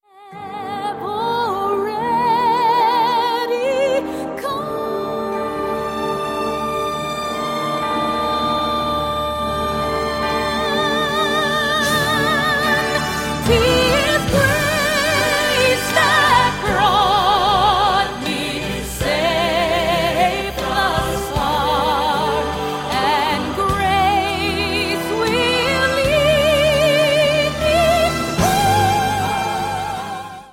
10 der besten weiblichen Stimmen
• Sachgebiet: Praise & Worship